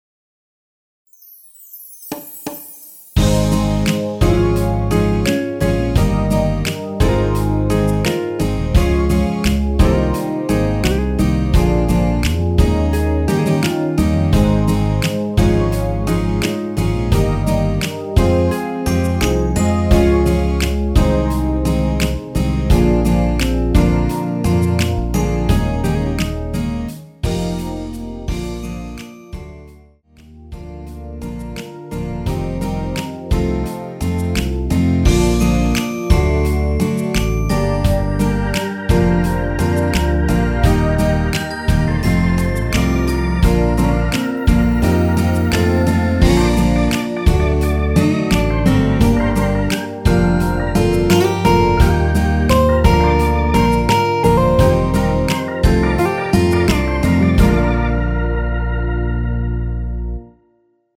엔딩이 페이드 아웃이라 라이브 하시기 좋게 엔딩을 만들어 놓았습니다.(미리듣기 참조)
Ab
◈ 곡명 옆 (-1)은 반음 내림, (+1)은 반음 올림 입니다.
앞부분30초, 뒷부분30초씩 편집해서 올려 드리고 있습니다.